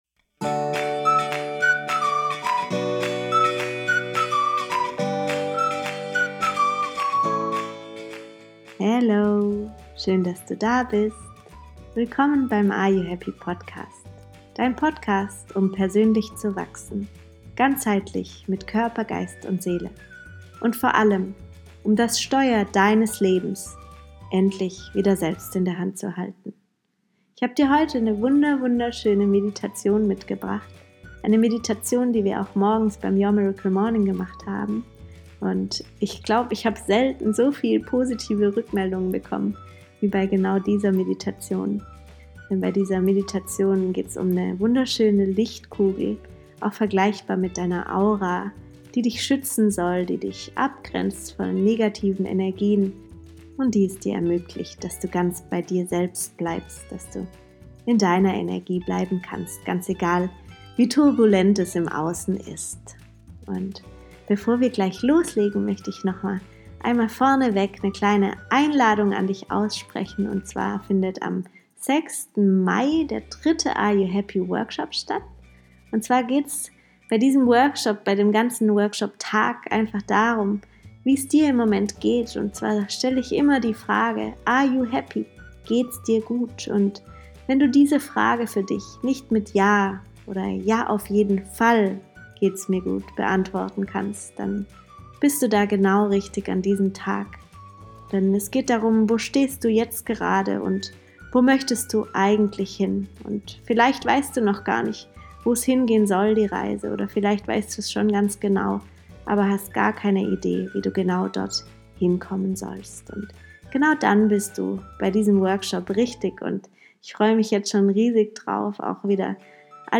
Lichtkugel - Meditation, um dein Energiefeld zu schützen ~ Ayu happy? Podcast